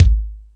Lotsa Kicks(43).wav